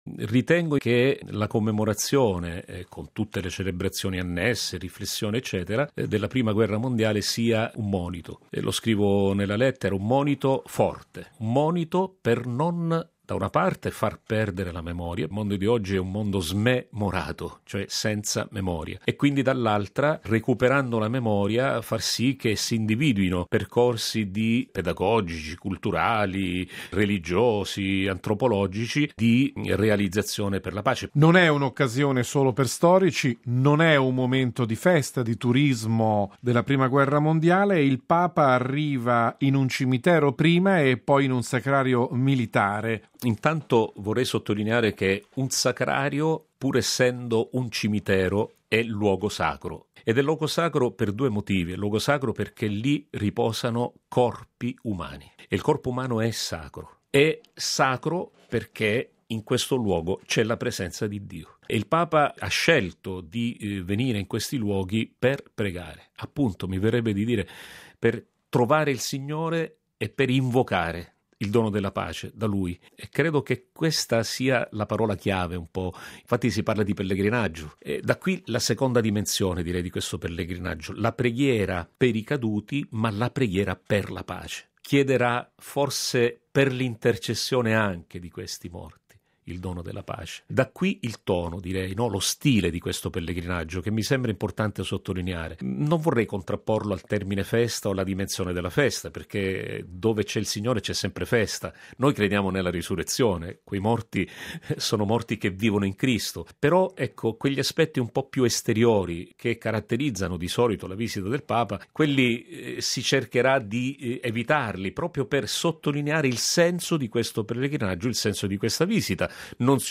ordinario militare per l’Italia